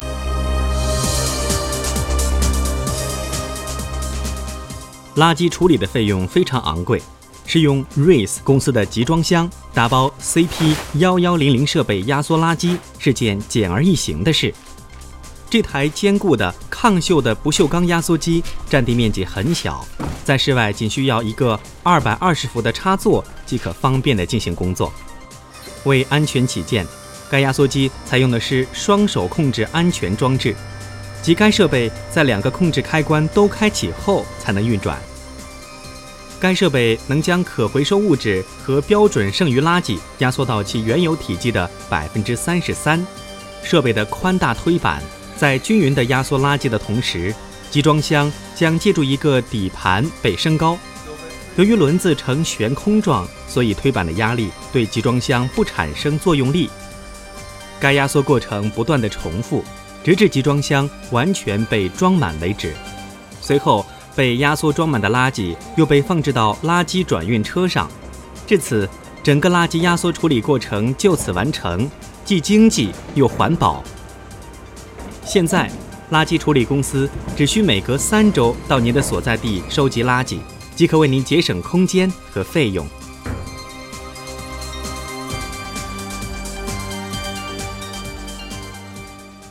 垃圾压缩设备CP 1100的实际操作短片(1分15秒)介绍: